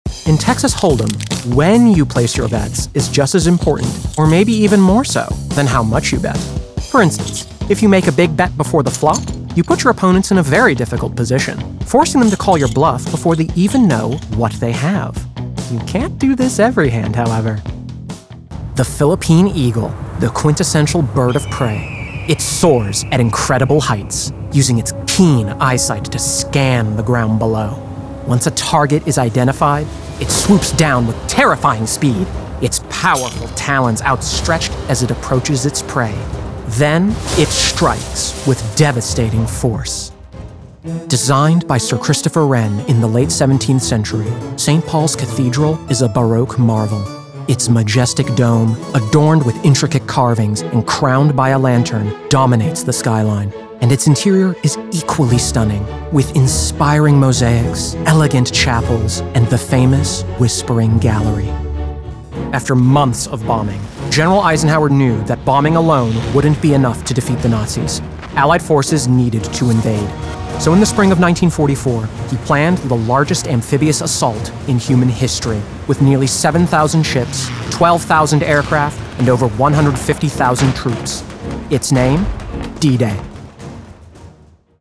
Narration Demo
General American English
Young Adult
Middle Aged